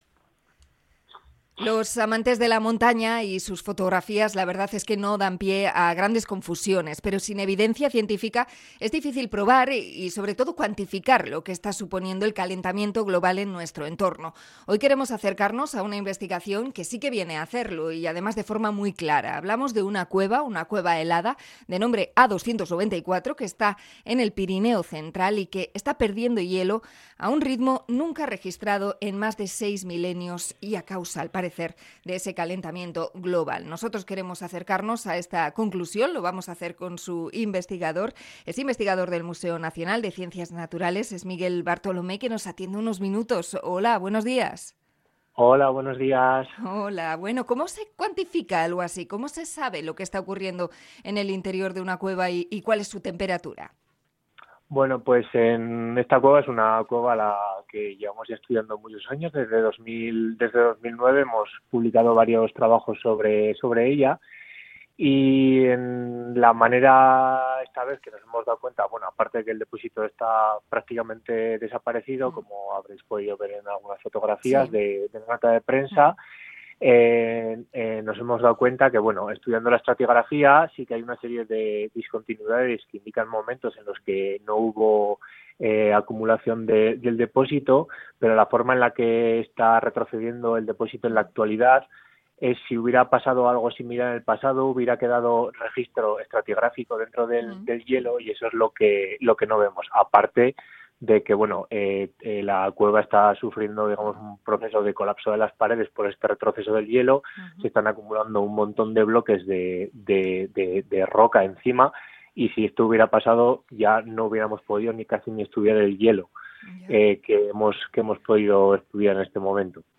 Entrevista a investigador sobre el deshielo en Pirineos